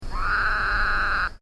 The advertisement call of the Rocky Mountain Toad is a loud bleating or snoring sound that has been compared to a muted sheep or calf bleating, or a snore, lasting 1 - 4 seconds.
Sound This is a recording of one repetition of the advertisement call of a Rocky Mountain Toad recorded at night in Riverside County.